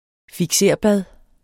Udtale [ figˈseɐ̯ˀ- ]